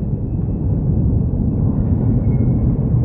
rumbling.ogg